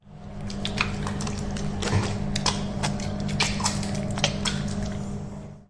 丹吉尔建筑声景 " 丹吉尔搓手
描述：这种声音已被记录在Tanger大楼，UPF Campus Poblenou。这是一个人在浴室里用肥皂和他的手擦在一起的声音。这是一种由肥皂，水和紧握的手制成的湿软的声音。
Tag: 肥皂 清洁 粘糊糊的 卫浴 水槽 丹吉尔 校园UPF UPF-CS14